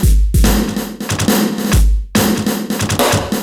E Kit 02.wav